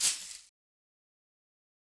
Shaker 2.wav